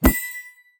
CollectModule_Res_booster_appear出现.mp3